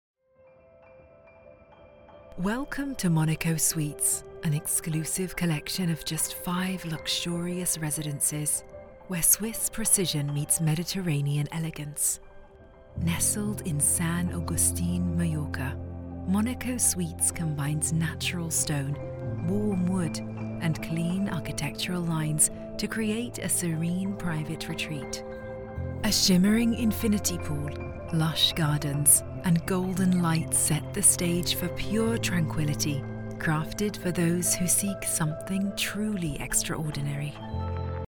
Corporate Videos
I have a professional home recording studio and have lent my voice to a wide range of high-profile projects.
LA Booth, Rode, Audient id4
DeepLow
TrustworthyAuthoritativeConfidentFriendlyExperiencedReliable